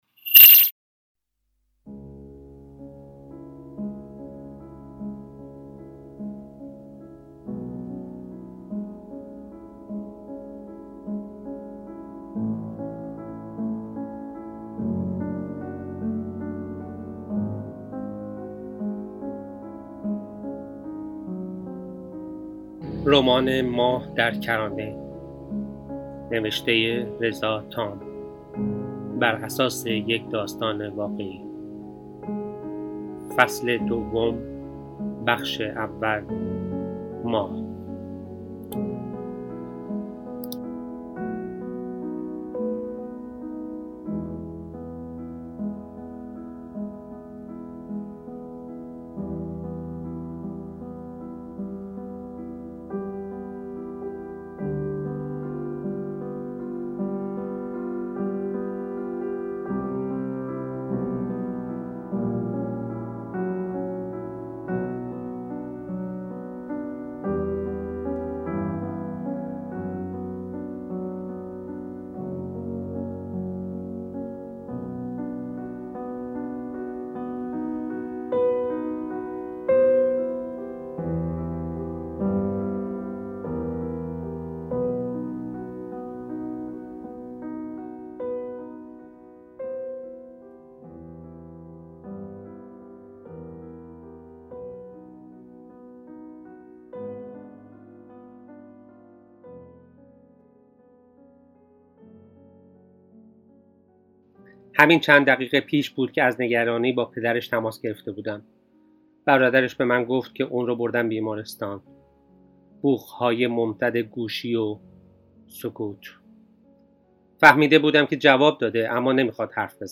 فصل-دوم-بخش-اول-کتاب-صوتی-ماه-در-کرانه.mp3.mp3